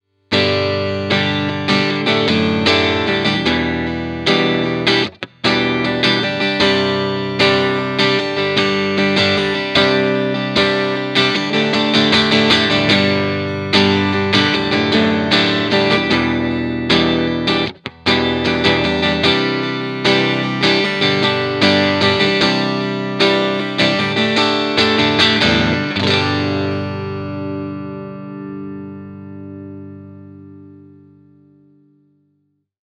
JTM45 Clean Tone Tubby Alnico
Very sweet.
JTM_CLEAN_ToneTubbyAlnico.mp3